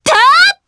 Frey-Vox_Attack3_jp.wav